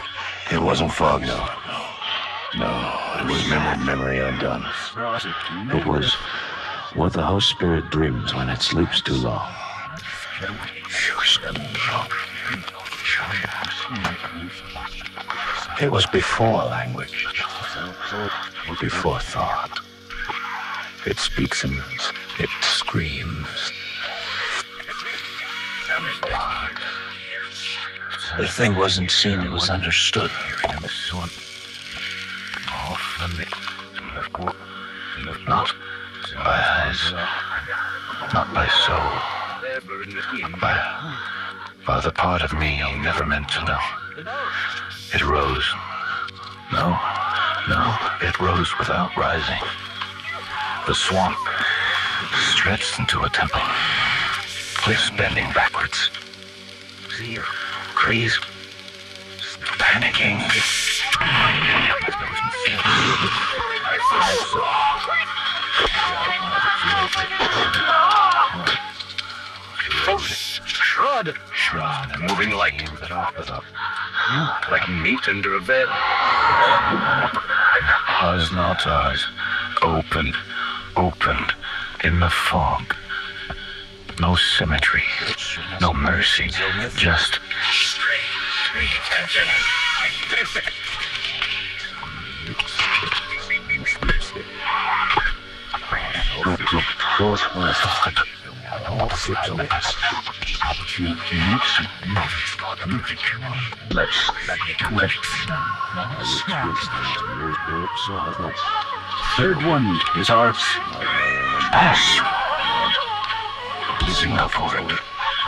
Despite extensive analysis, the persistent background noises, intermittent chanting, distorted whispers, and unexplained audio interference remain unidentified.
Recovered Audio Transcript
Recording Device: Standard-issue Ordo field audio recorder
Recording Location: Secure interview room, Temple Alabaster Sanitarium (post-rescue interview session)